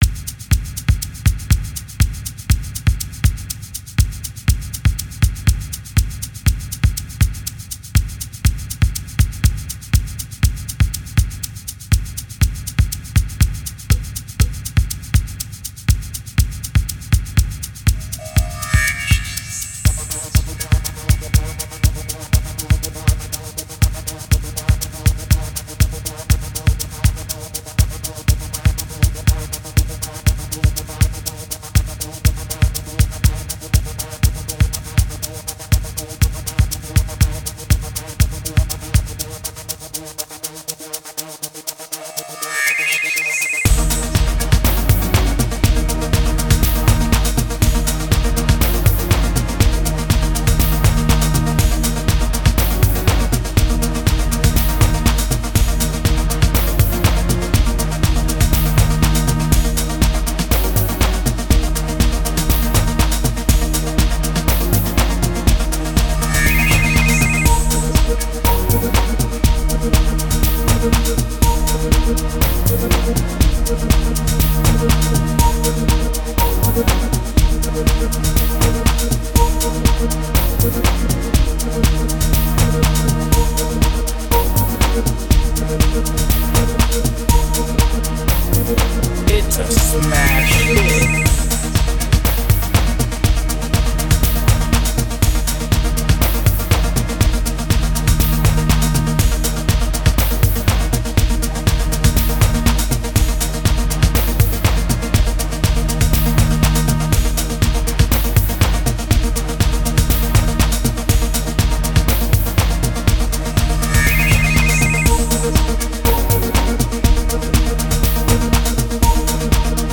03:57 Genre : House Size